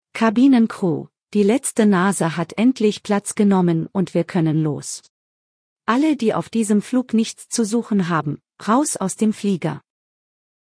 BoardingComplete.ogg